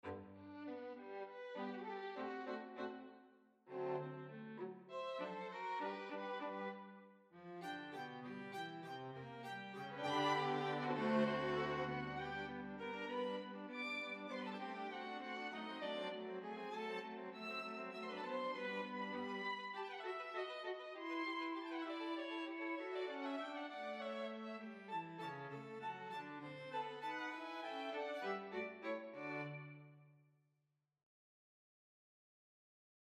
There are also solo strings in the SE (Special Edition) VOL.1
They sound like this (using the VSL provided template)